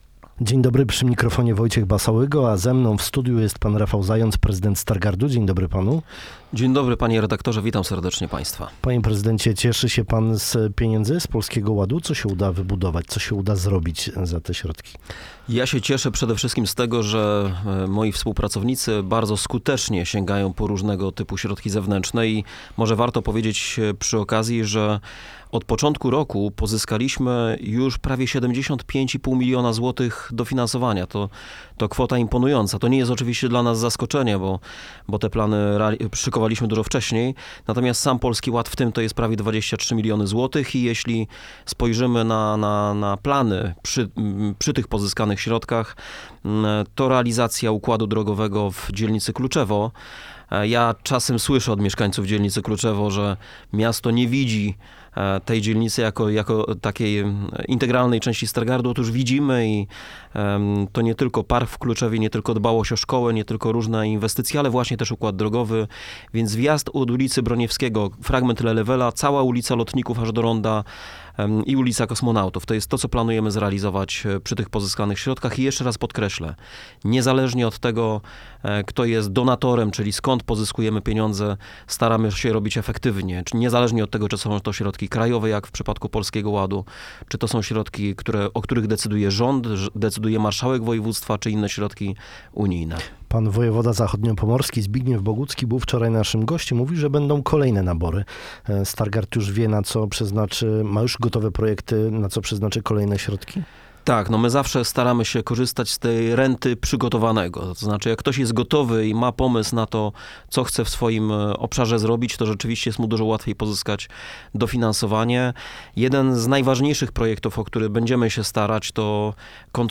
– Wnioskowaliśmy o środki na przebudowę dróg właśnie w tej części miasta. Dziękuję moim współpracownikom, którzy tak skutecznie pozyskują dofinansowania z różnych źródeł – mówił prezydent Rafał Zając na antenie Twojego Radia.